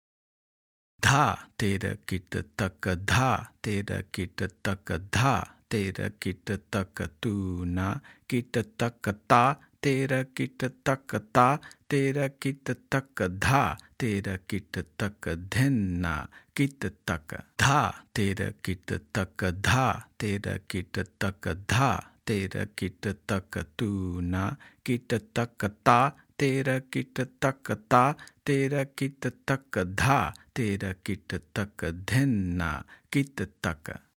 1x Speed – Spoken